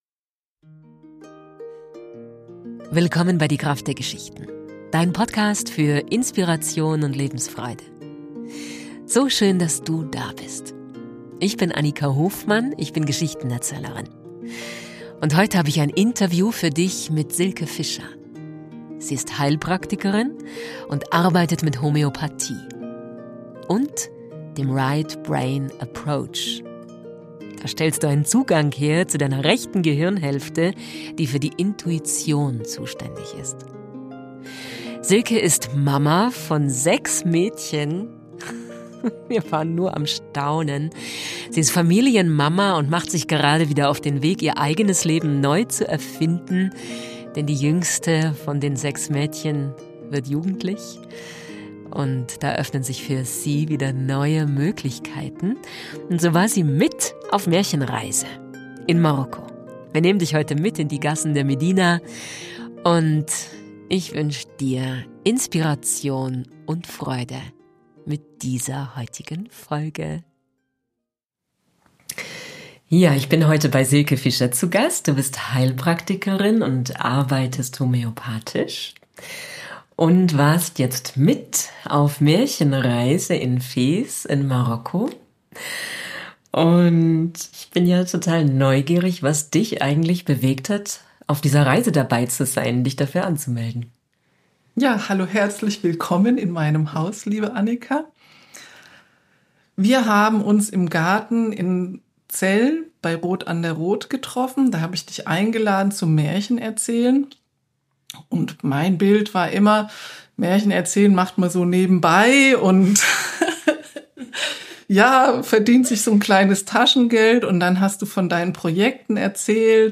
Diesmal habe ich ein Interview für Dich.